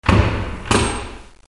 Portone che si chiude
Rumore sordo di una grande porta che si chiude accompagnato da un secondo rumore con ambiente.
BIGDOOR2.mp3